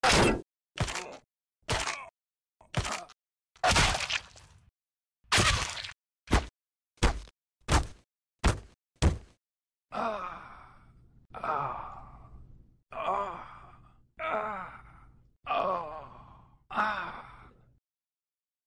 Сборник звуков урона, смерти, попадания в голову, бронежилет и шлем из игры Контер Страйк